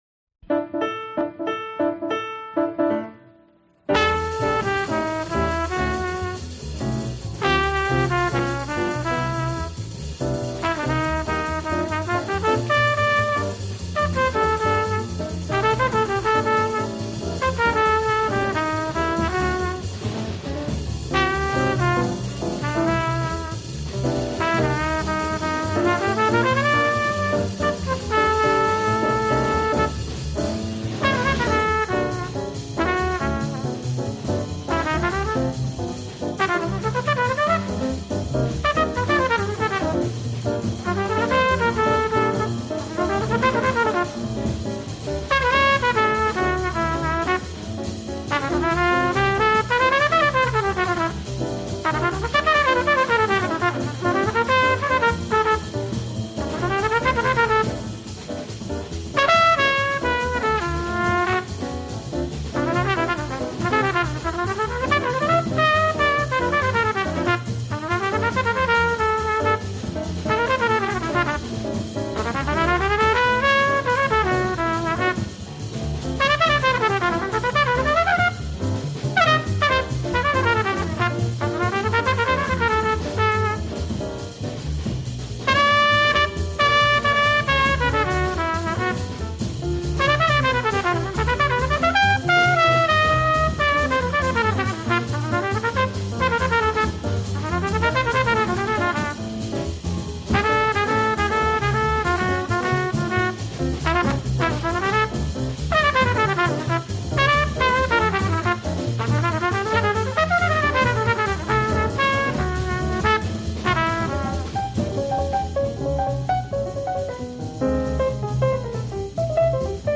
precise, exuberant classic.
EnsembleInstrumentalJazzMusic
Jazz Great